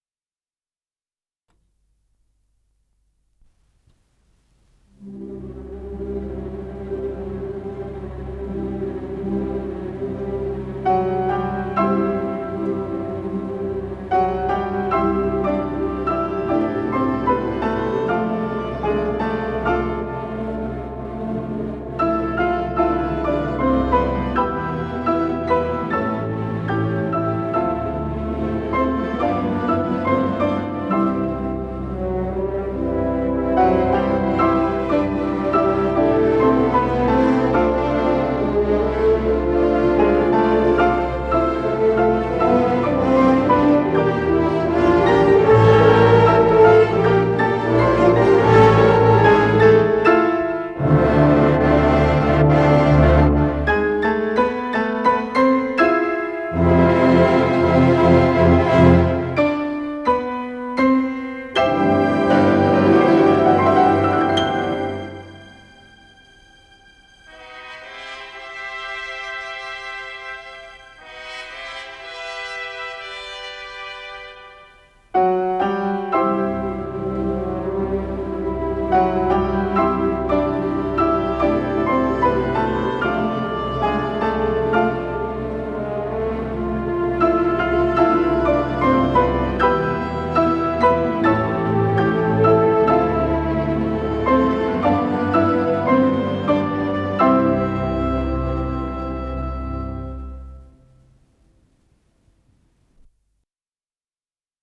с профессиональной магнитной ленты
до мажор